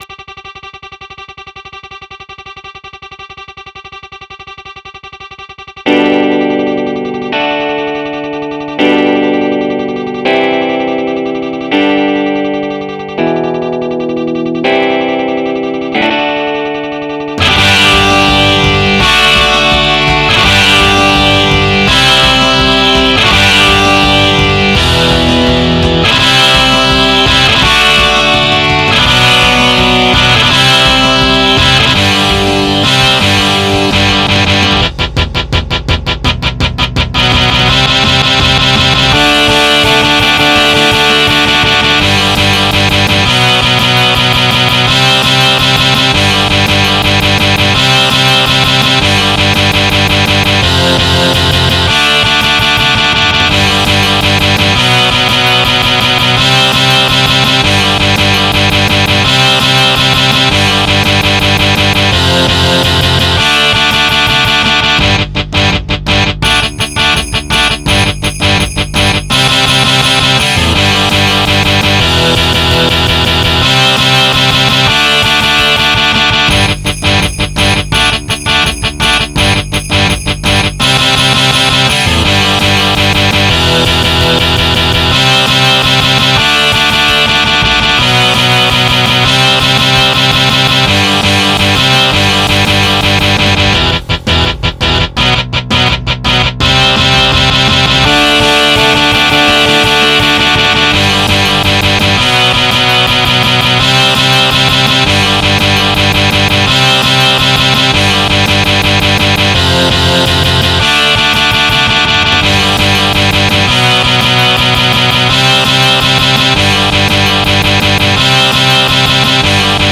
MIDI 75.49 KB MP3
instrumental midi